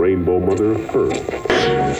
120BPMRAD8-L.wav